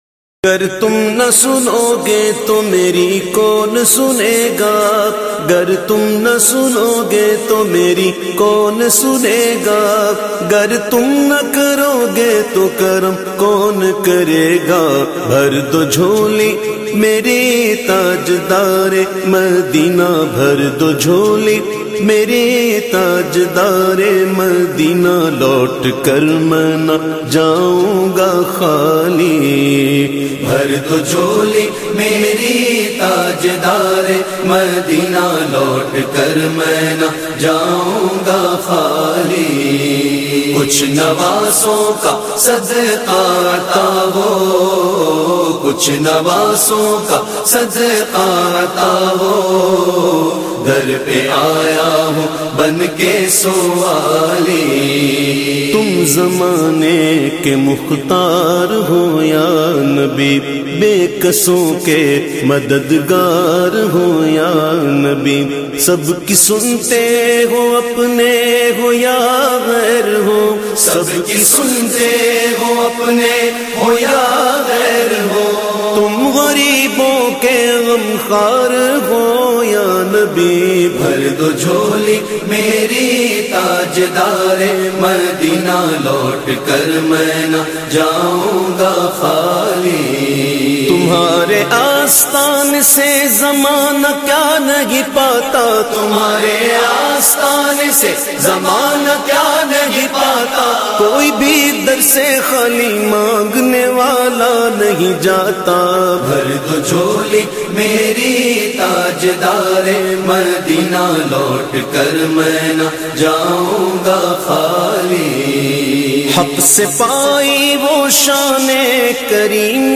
Naat Sharif
recited by famous Naat Khawan of Pakistan